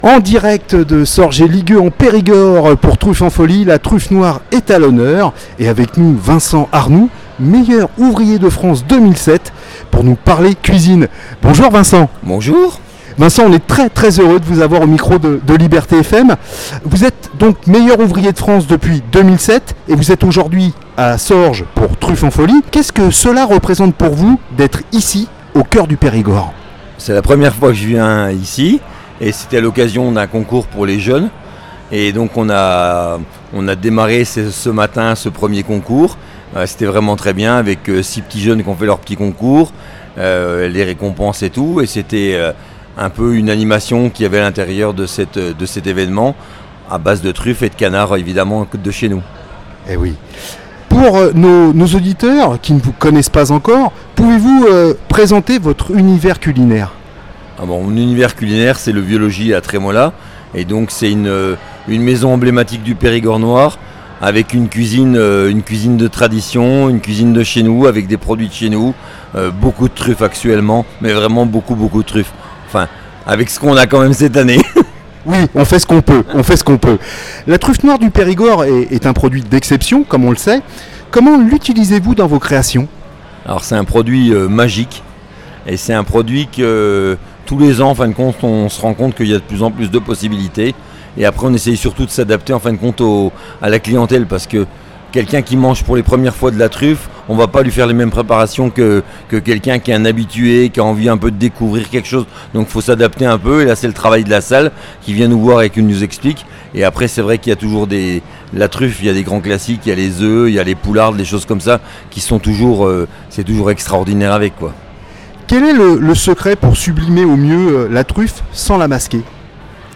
En direct de Sorges-et-Ligueux-en-Périgord pour “Truffes en Folie”.